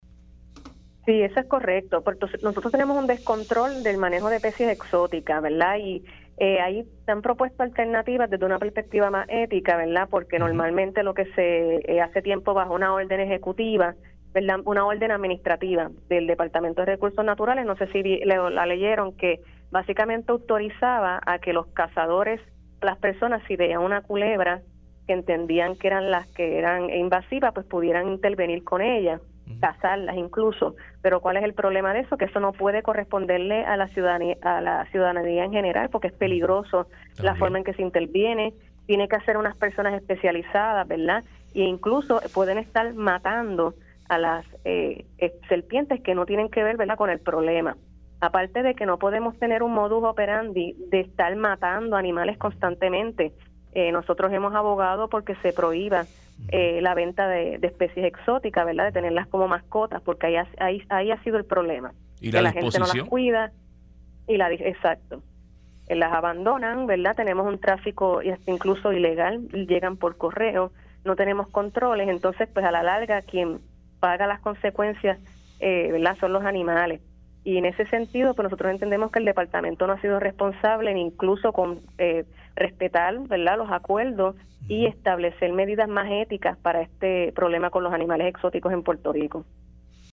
entrevista para Dígame la Verdad